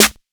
Rich Dist SNare.wav